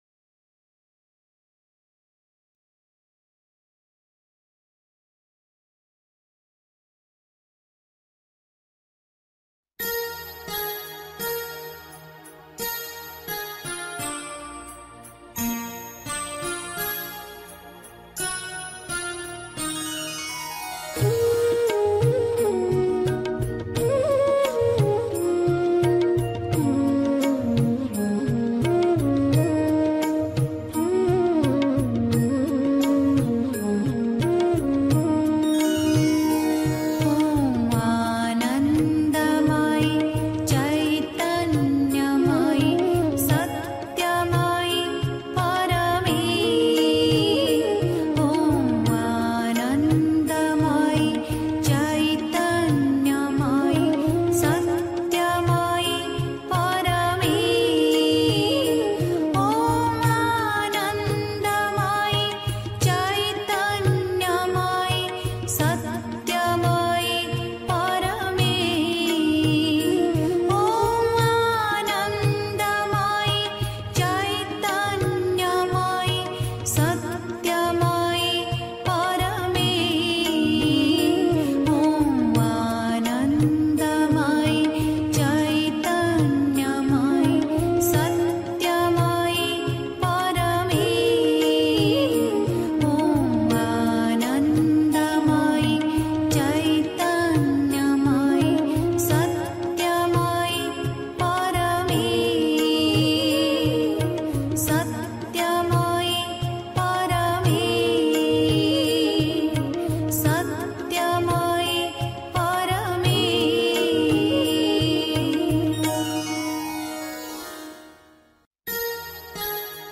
1. Einstimmung mit Musik. 2. Lache! (Die Mutter, White Roses, 7 December 1962) 3. Zwölf Minuten Stille.